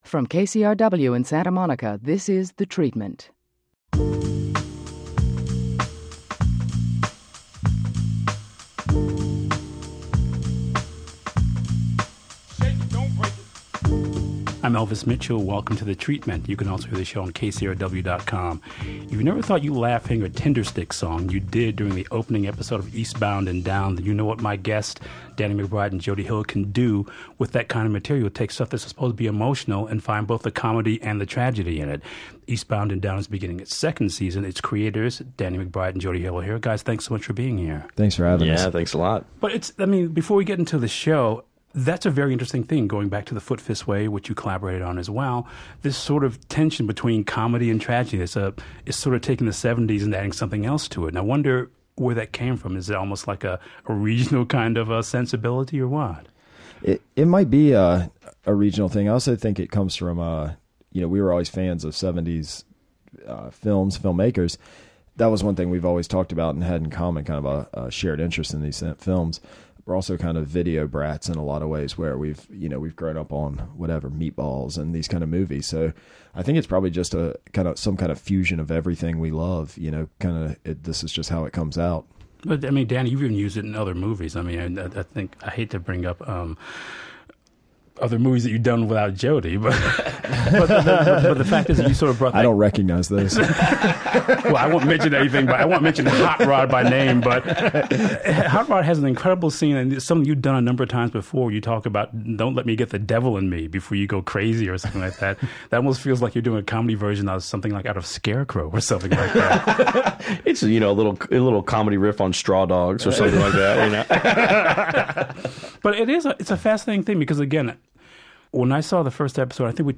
Hill and McBride talk (as well as laugh!) about meeting fans of the show that like – and are like - character Kenny Powers (McBride), shooting the show as if it were a feature film, the pitfalls of following one's dream, script-writing as if the show were a drama, and using music to counter-balance the action on screen.